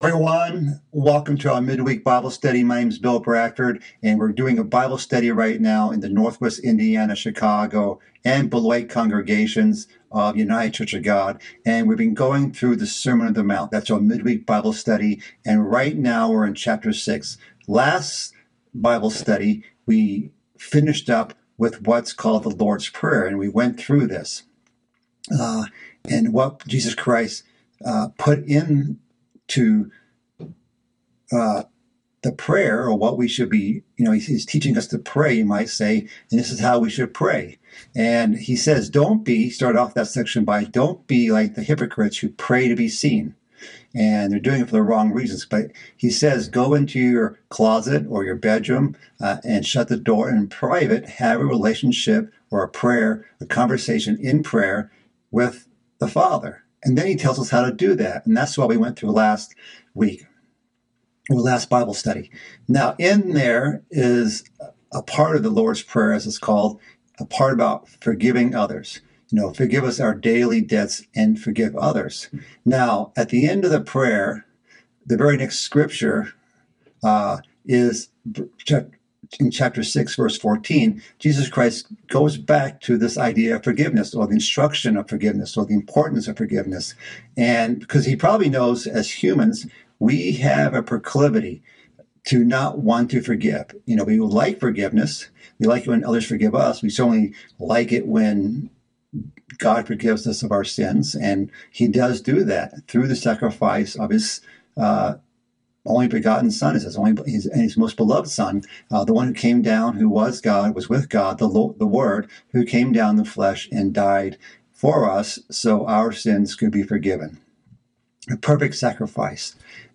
This is part of a mid-week Bible study series about the sermon on the mount. In this part, the topics of forgiveness and fasting are covered.